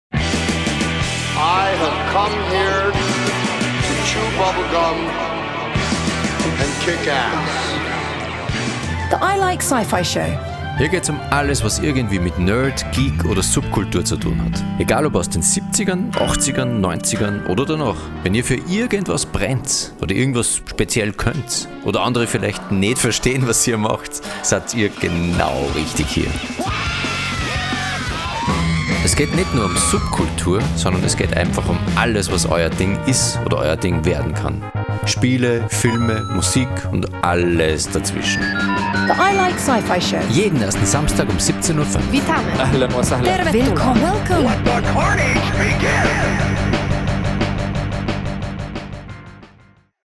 Sendungstrailer